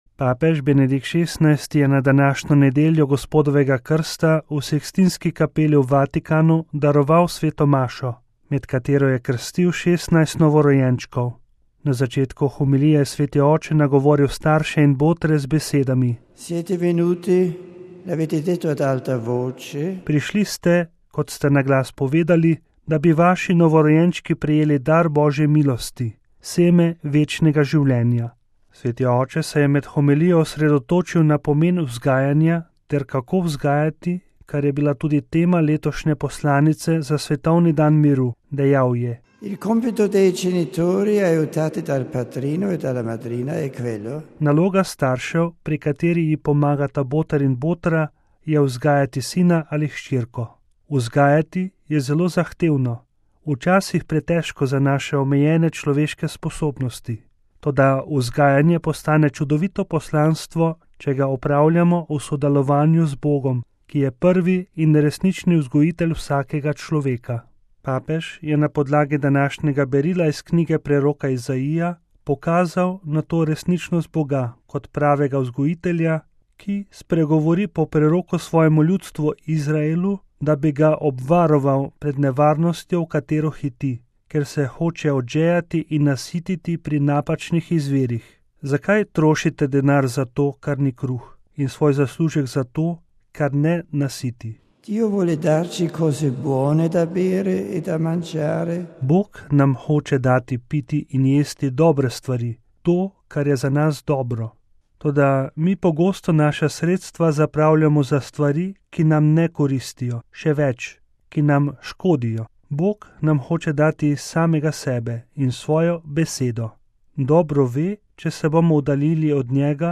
VATIKAN (nedelja, 8. januar 2012, RV) – Papež Benedikt XVI. je na današnjo nedeljo Gospodovega krsta v sikstinski kapeli v Vatikanu daroval sveto mašo, med katero je kstil 16 novorojenčkov.